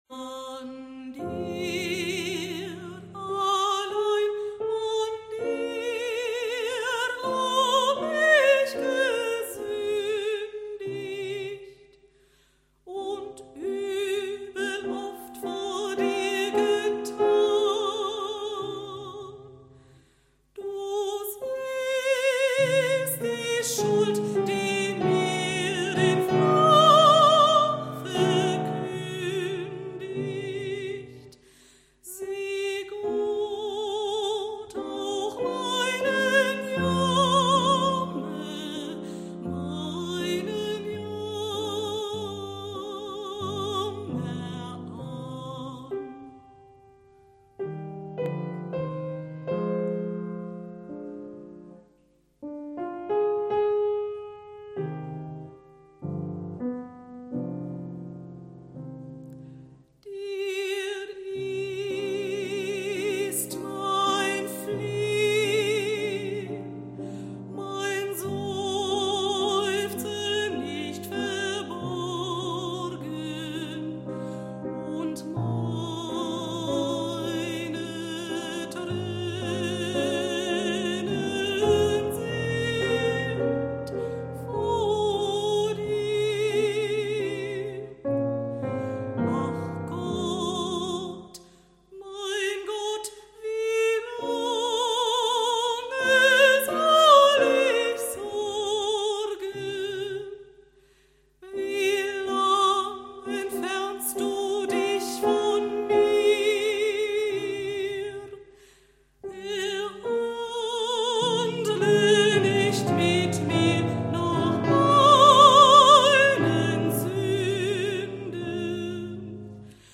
Sängerin
Klavier